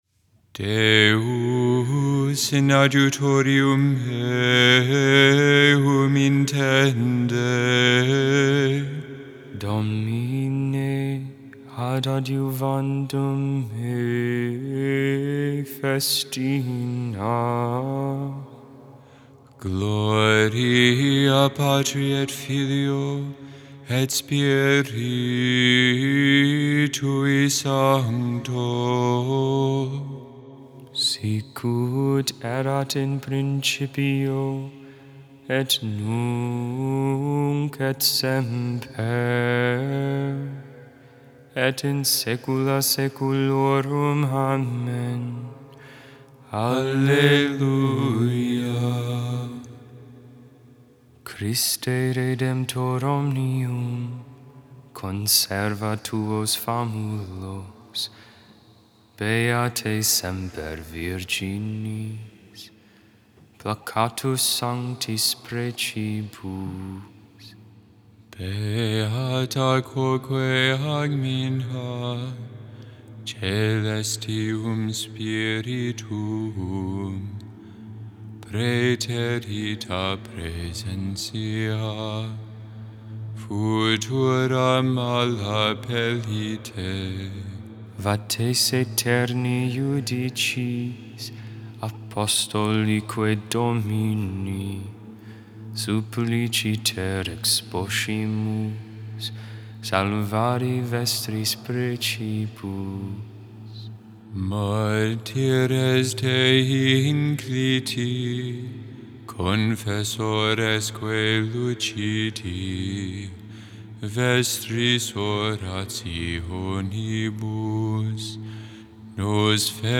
10.31.22 Vespers, Monday Evening Prayer
Hymn